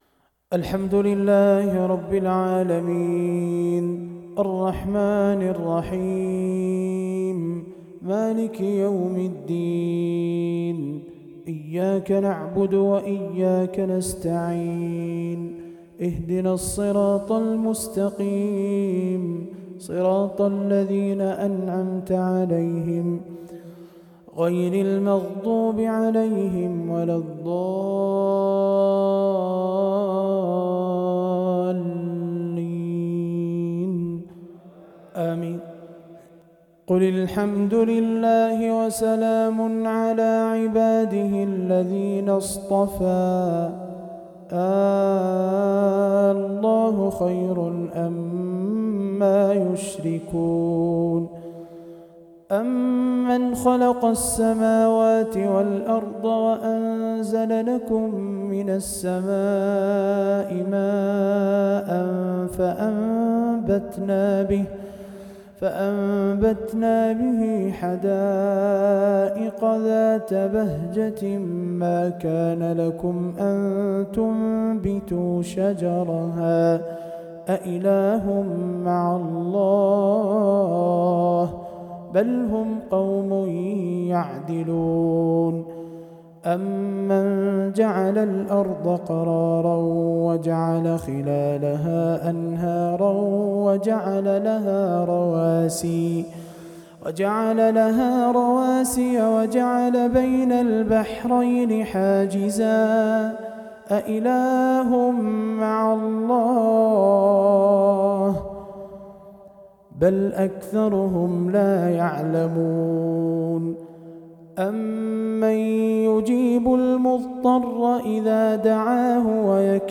تلاوة مميزة من سورة النحل